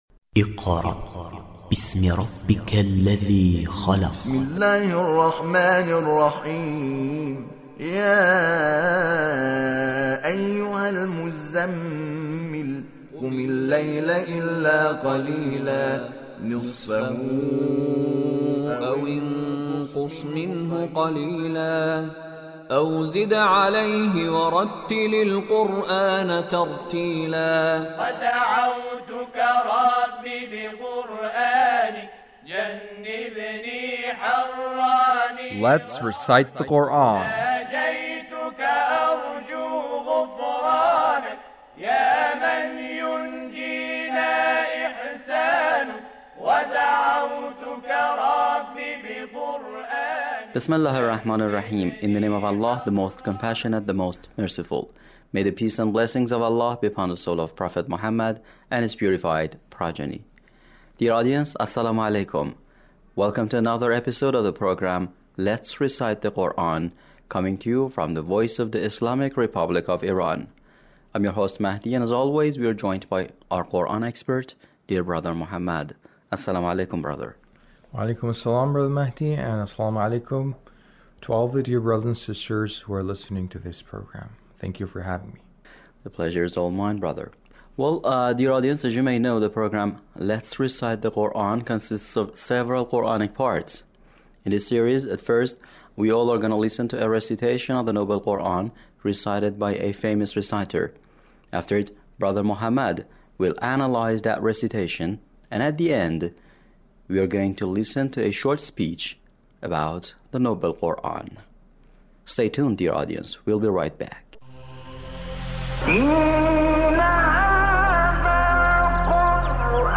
Mohammad Seddiq Menshawi's recitation